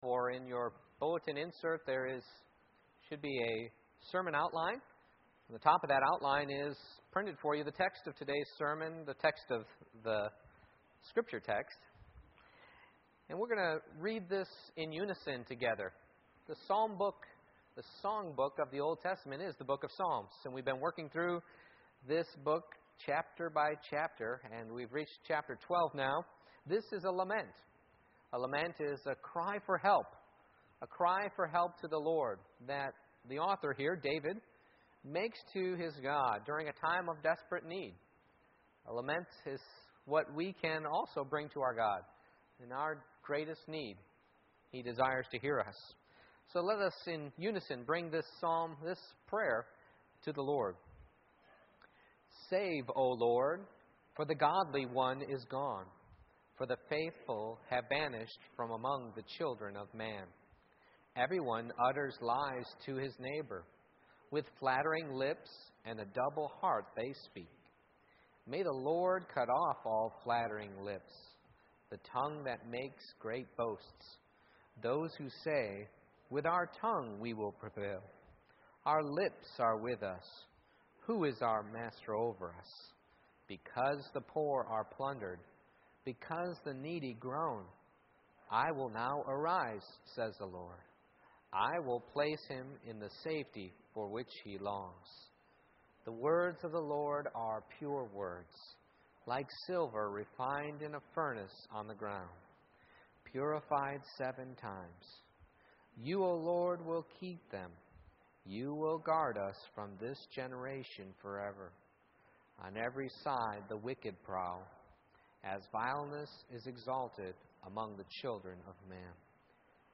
Psalm 12:1-8 Service Type: Morning Worship I. The Desperate Cry